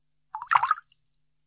水厕 " agua6
描述：一包6个厕所水样中的一部分，用除噪剂和eq
标签： 滴落 现场记录 厕所
声道立体声